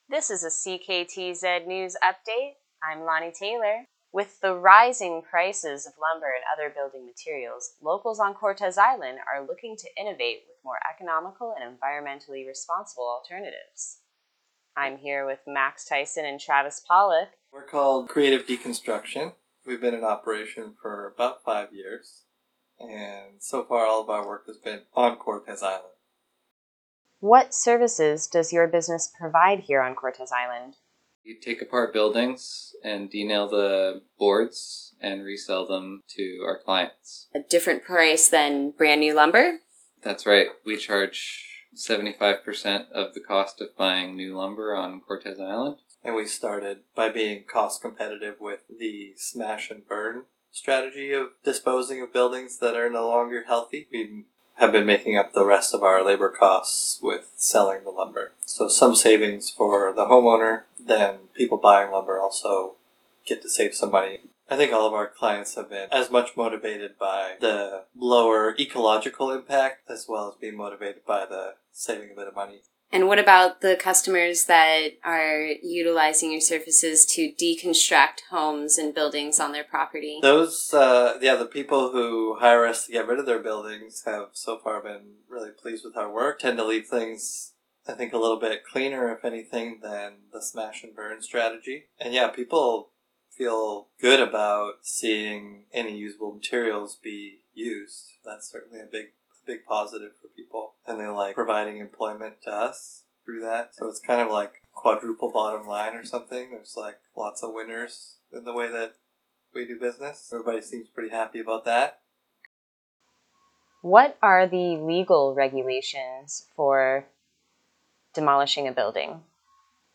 Listen to the CKTZ interview below: Download Audio Prev Previous Post Halifax man sober after 30 years of suffering from cocaine addiction Next Post Six Nations Jr. A Lacrosse Club excited for upcoming 2022 season Next